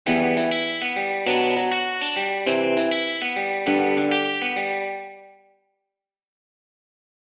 例えば「Em-Bm-C-G」というコード進行。
そこでBmの最後に「３弦開放弦のG音」を入れていきます。
しかも繋ぎは楽になりスムーズで、コードの最後がすべて「G音」なので統一性もありますよね。
こんな感じです⬇（MIDI演奏）